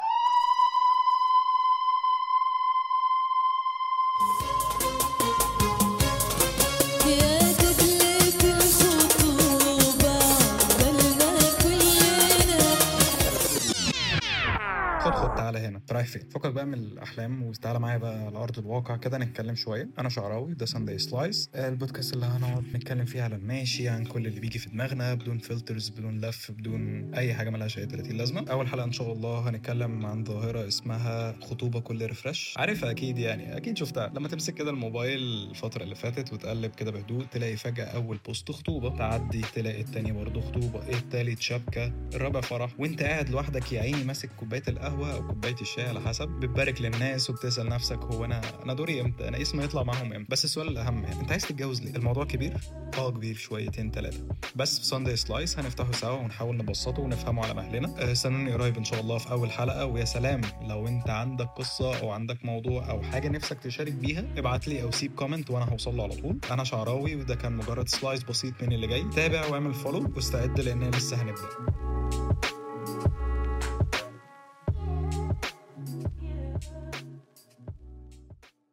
Genres: Comedy, Stand-Up
Trailer: